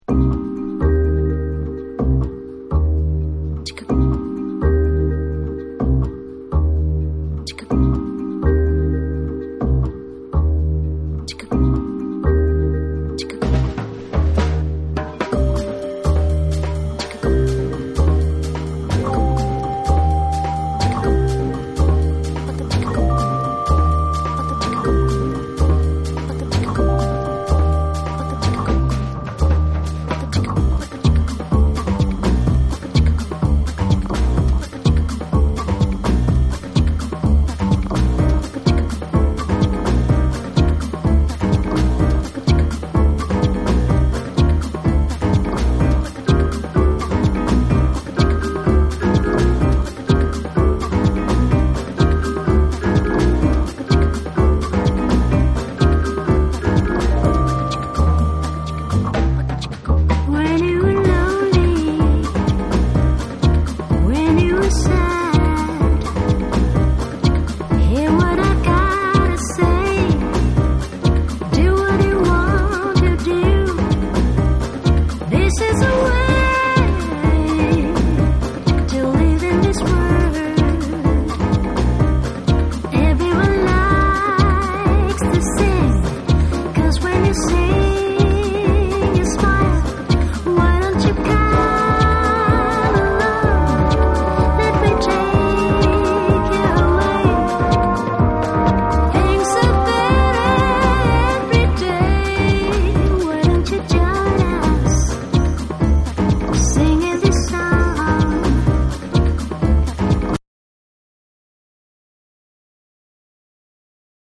JAPANESE / SOUL & FUNK & JAZZ & etc